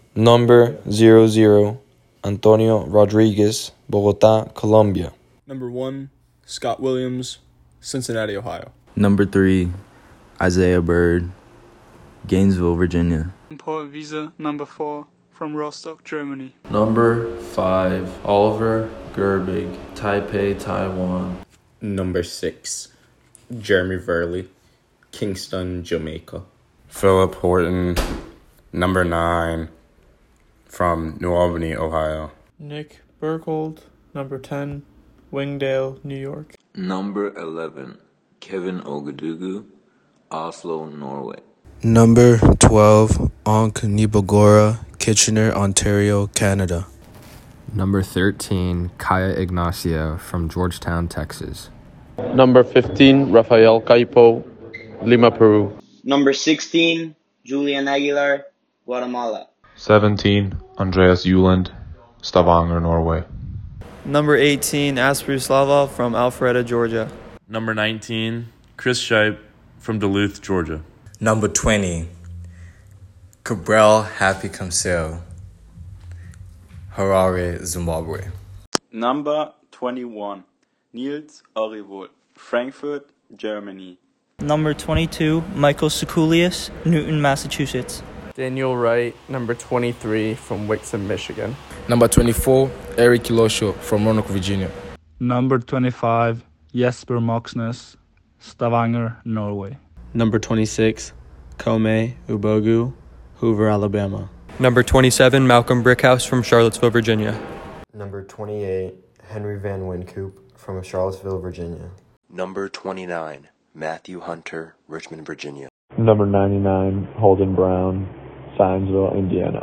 2021 Player Pronunciations